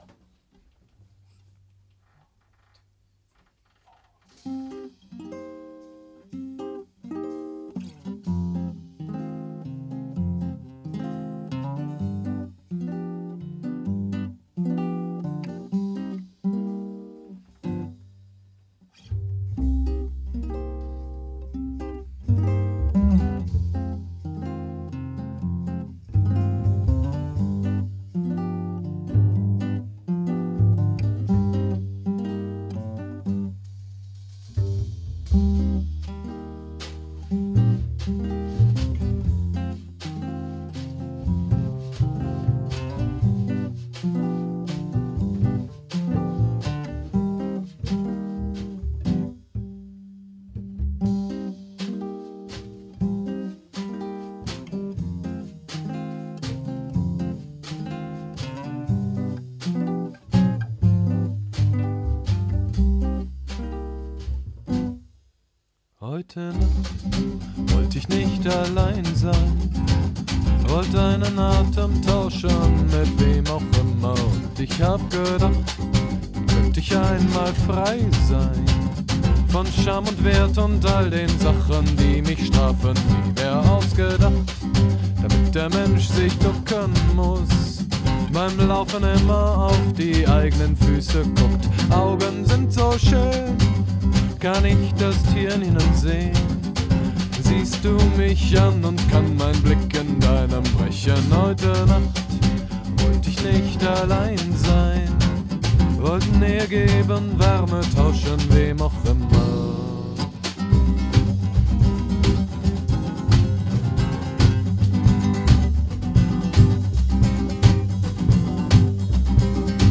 Lied
an den Trommeln
am Kontrabass